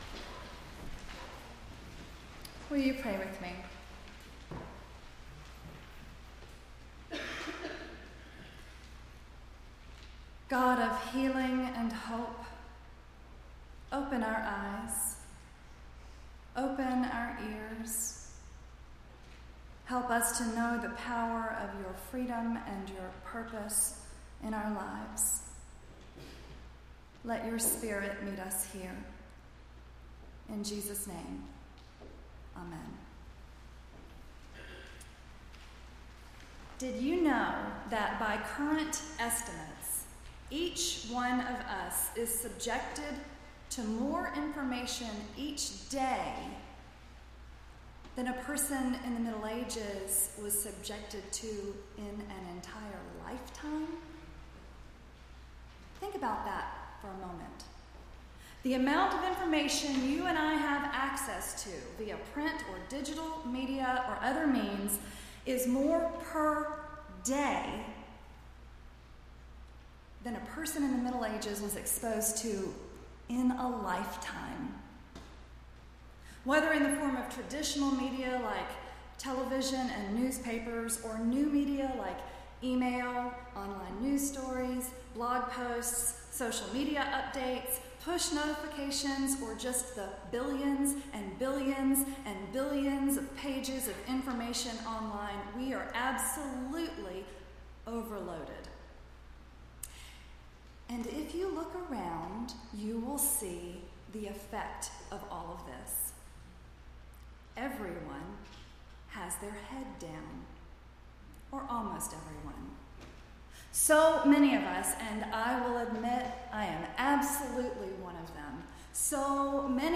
8-21-16-sermon.mp3